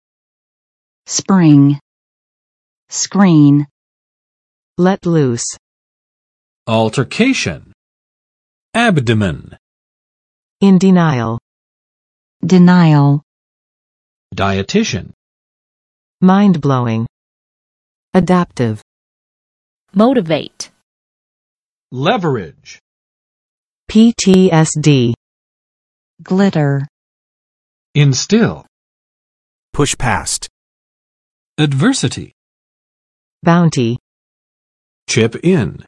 [sprɪŋ] v. 突然出现，涌现
spring.mp3